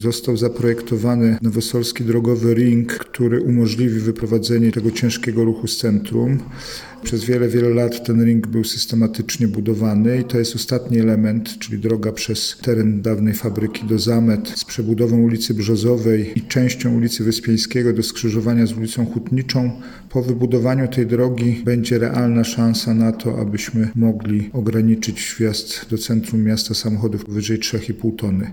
– To ostatni element programu wyprowadzającego ruch ciężarówek z centrum Nowej Soli – powiedział prezydent Jacek Milewski: